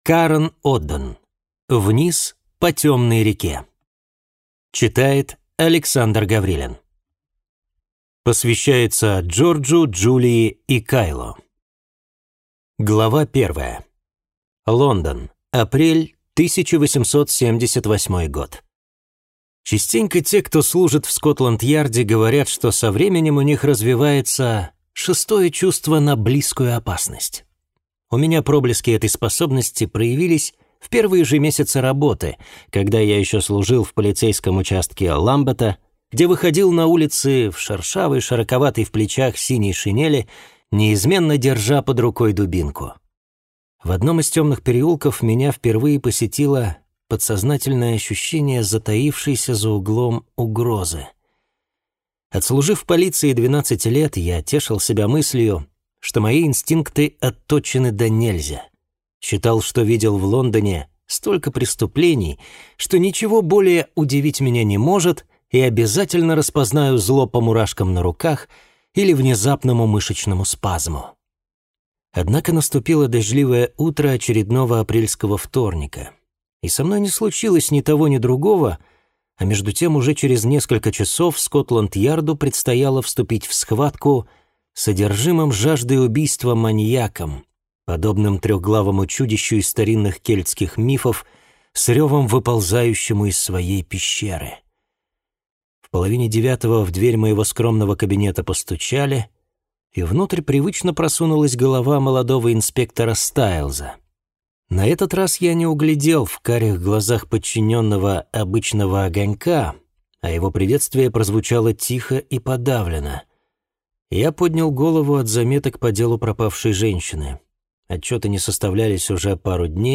Аудиокнига Вниз по темной реке | Библиотека аудиокниг